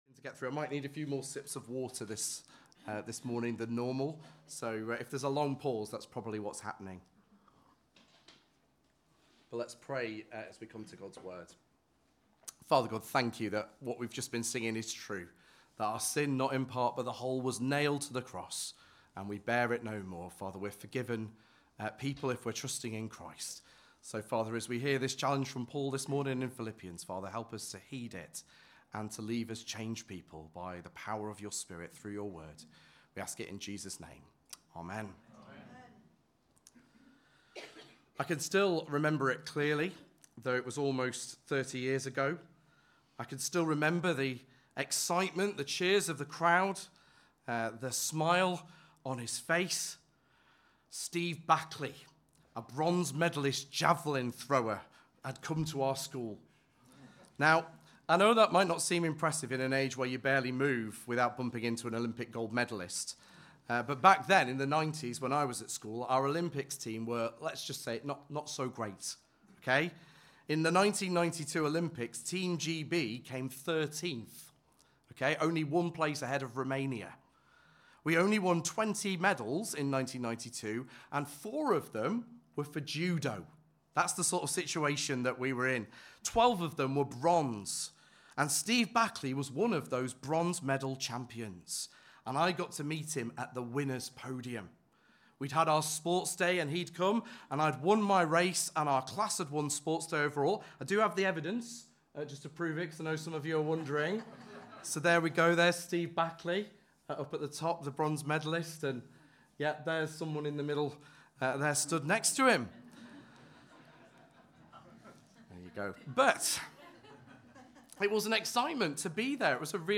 Watch or listen to our back catalogue of Bible talks from the last few years.